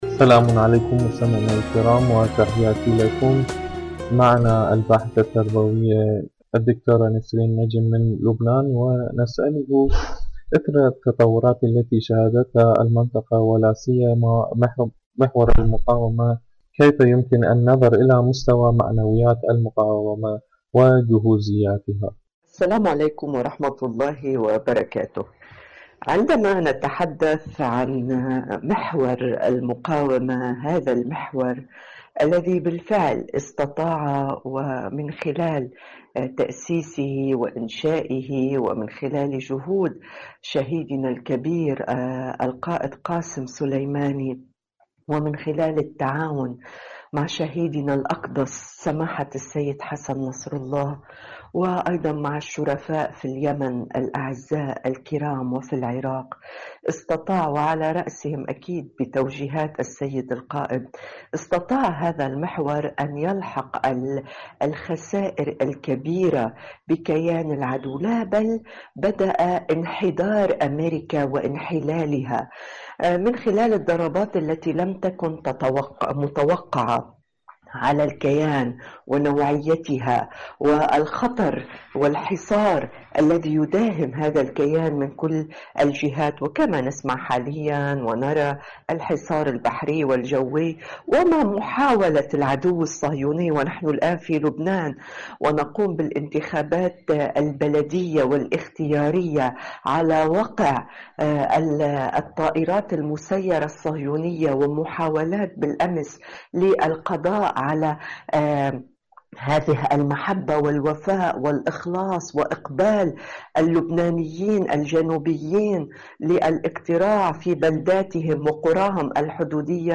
إذاعة طهران- دنيا الشباب: مقابلة إذاعية